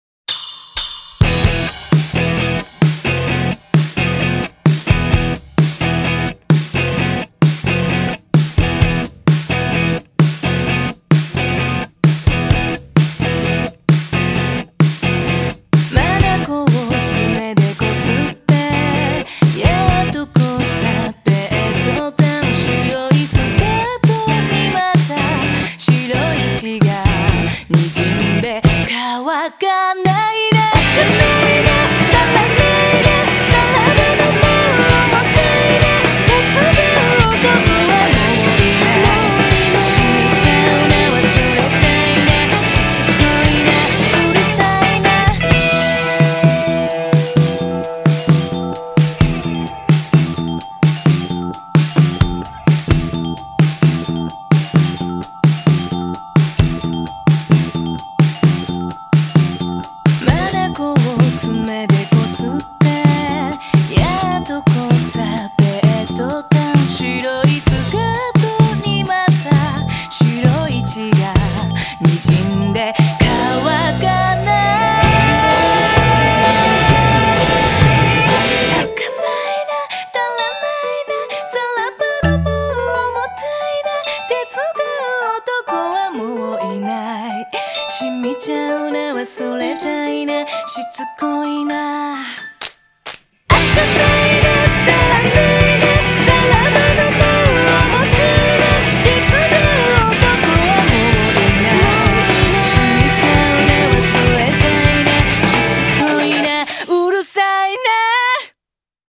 前者在空洞中透露出紧实干练的力量，后者则以喧嚣掩饰空虚和匮乏。